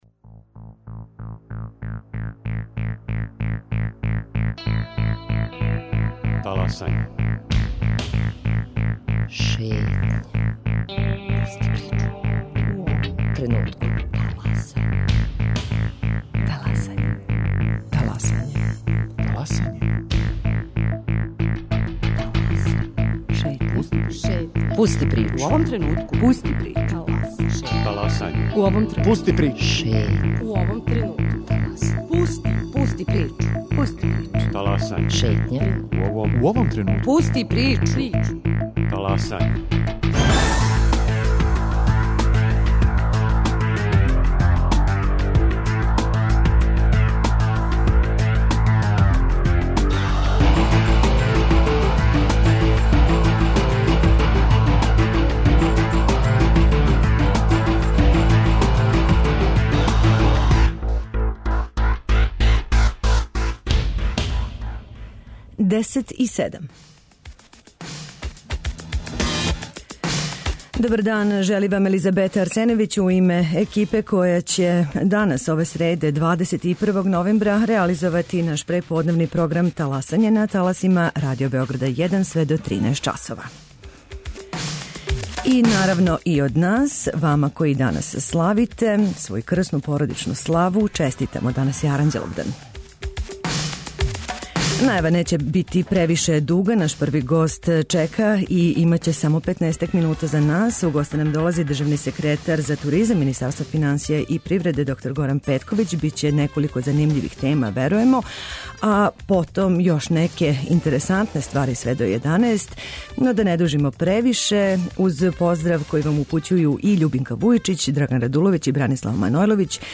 Неколико је повода зашто смо у госте позвали државног секретара за туризам у Министарству финансија и привреде др Горана Петковића. На управо завршеном Сајму туризма у Шангају, наша земља имала је врло запажен наступ.